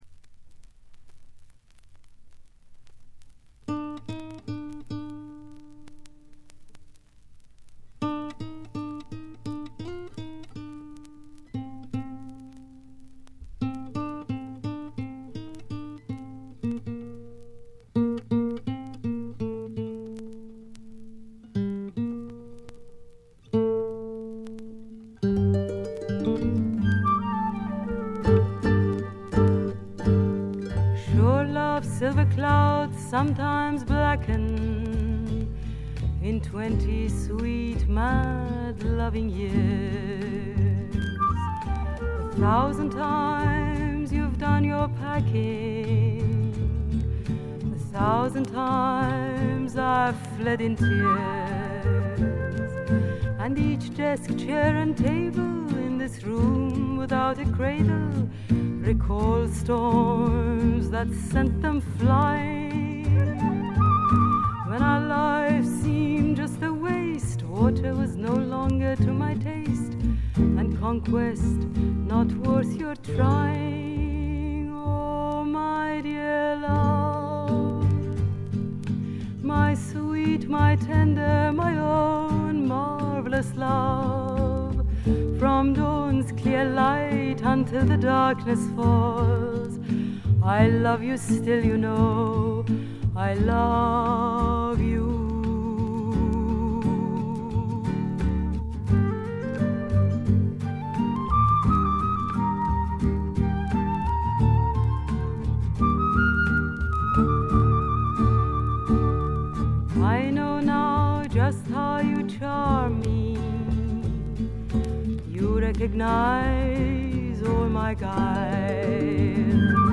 静音部でのバックグラウンドノイズ、軽微なチリプチ。
全体にクールに飛翔するフルートがとても印象的でいい味をかもし出しています。
ともあれ、どんな曲をやってもぞくぞくするようなアルトヴォイスがすべてを持って行ってしまいますね。
試聴曲は現品からの取り込み音源です。